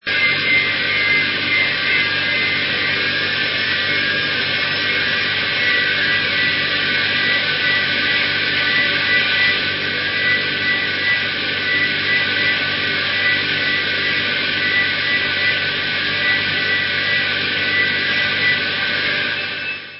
-ULTRA RARE MIND EXPANDING FUZZ FILLED GEMS! -